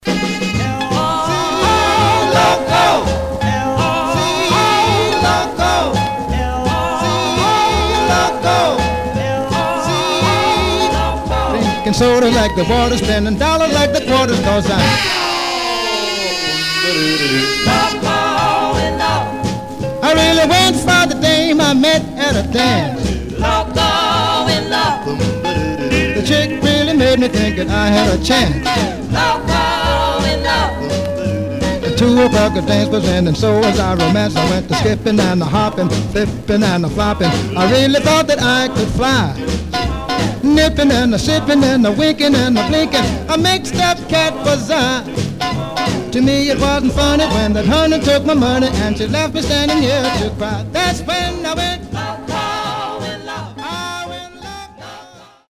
Rocker
Some surface noise/wear
Mono
Male Black Group